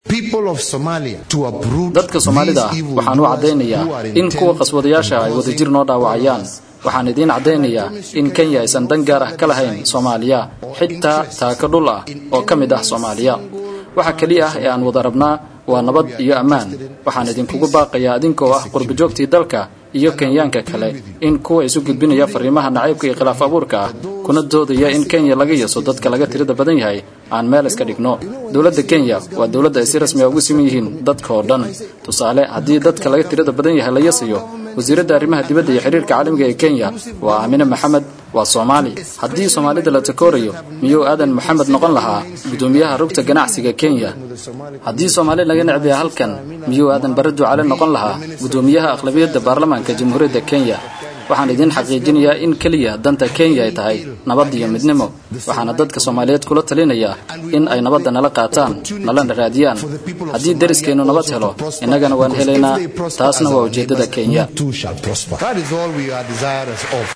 Nairobi(INO)–Madaxweynah Dalka Kenya Uhuru Kenyatta oo ka hadlayay kulan ay isugu yimaadeen Qurba joogta Dalka Kenya oo ka dhacay Nairobi ayuu ka jeediyay Khudbad dhinacyo badan ka hadleysay.